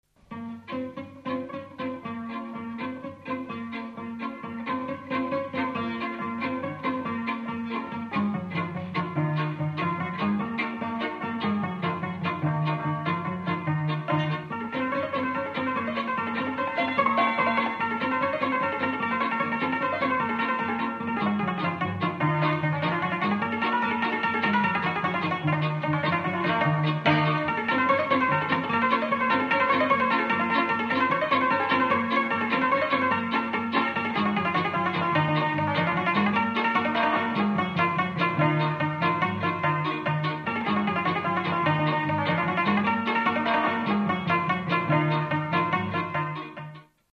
Dallampélda: Hangszeres felvétel
Dunántúl - Sopron vm. - Sopron
Műfaj: Dudanóta
Stílus: 6. Duda-kanász mulattató stílus